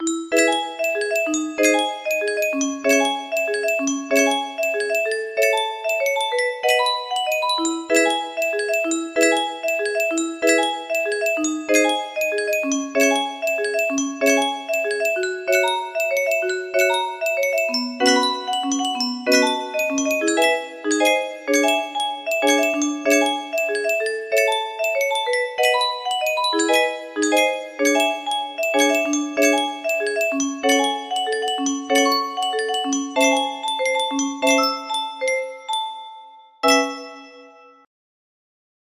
Full range 60
brief verse, chorus, and finish